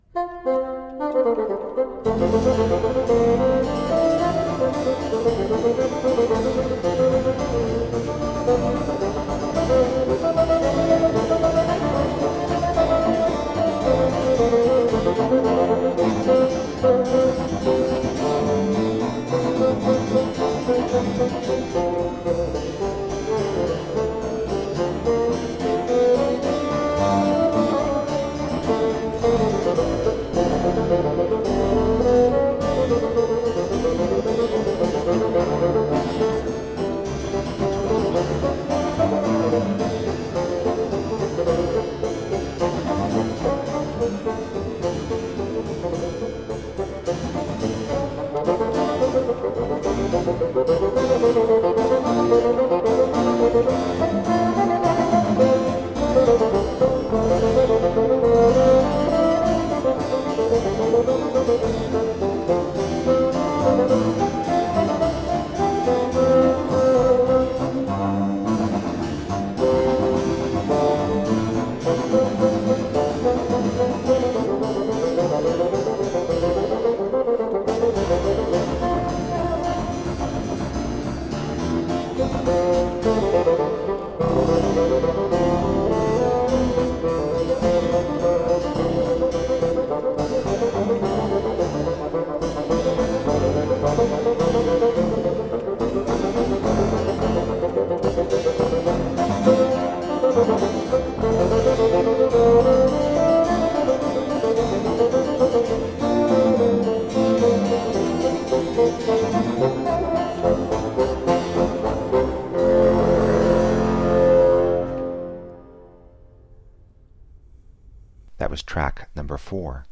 Buoyant baroque bassoon.
bright, warm tones